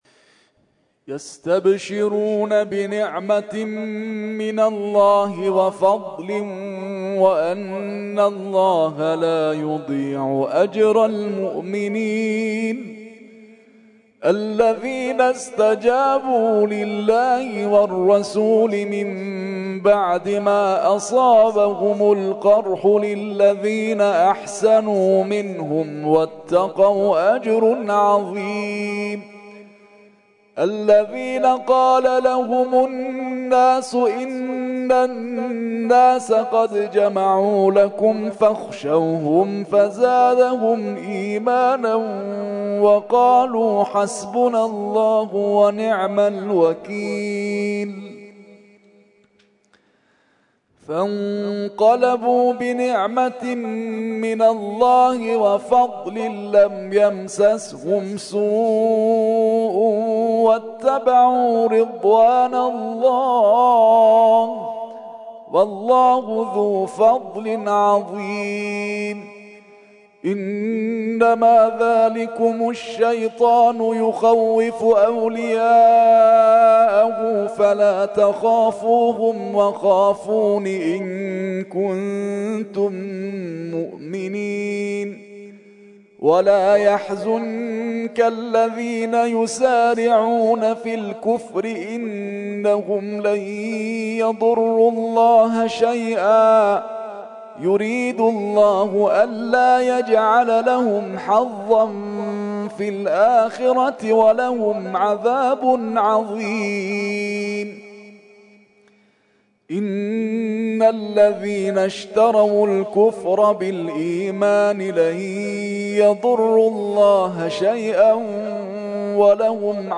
ترتیل خوانی جزء ۴ قرآن کریم در سال ۱۳۹۴